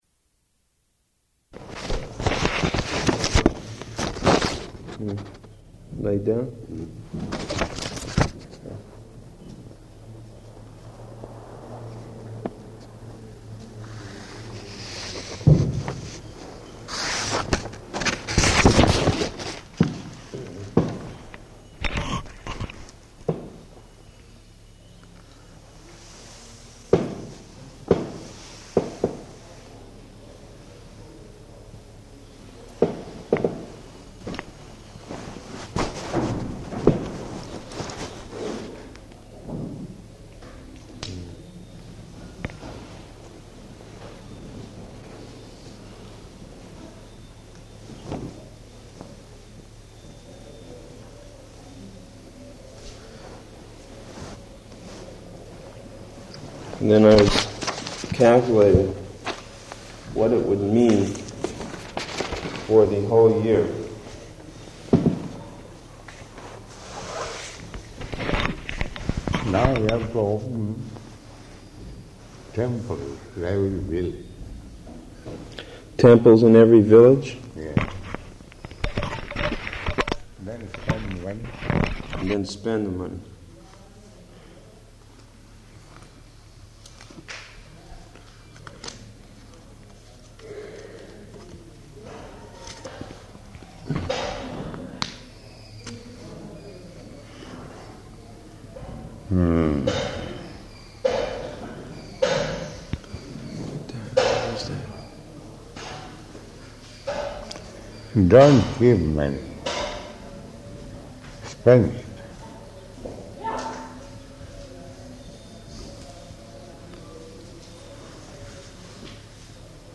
Room Conversation
-- Type: Conversation Dated: November 3rd 1977 Location: Vṛndāvana Audio file